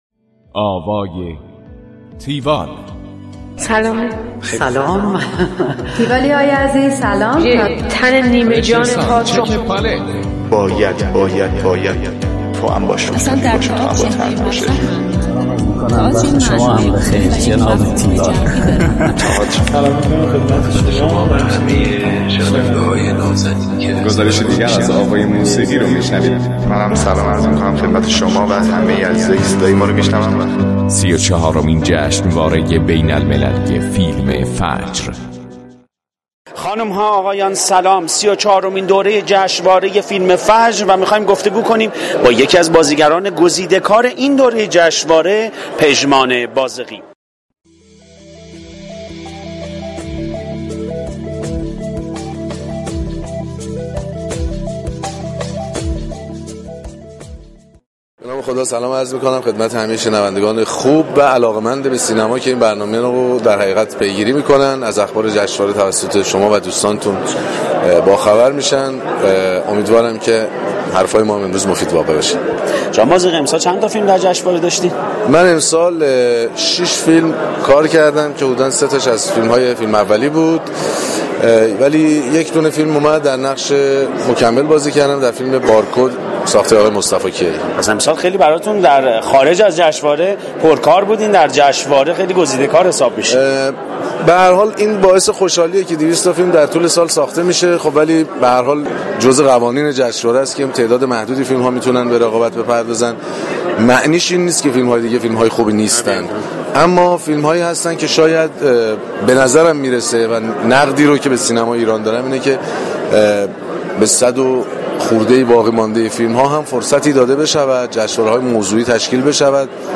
گفتگوی تیوال با پژمان بازغی